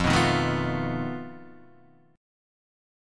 F 7th Flat 5th